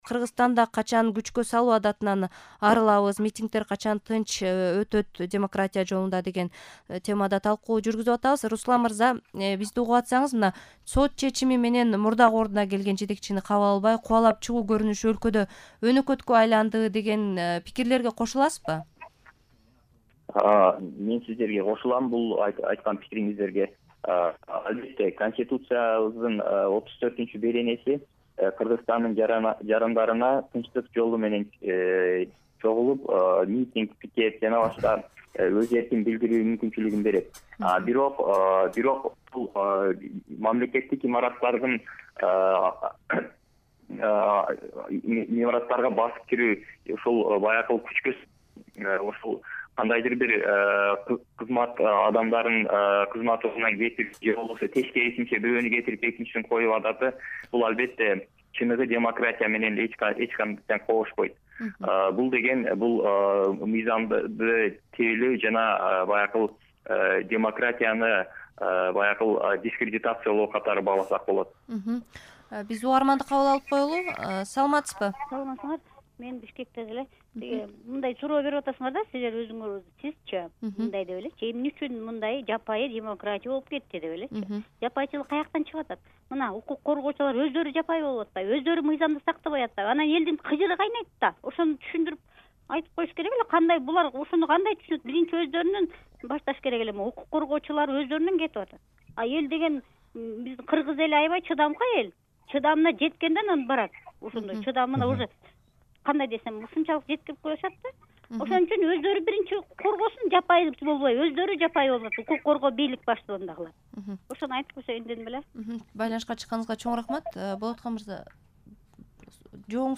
Митингдер тууралуу талкуу (1)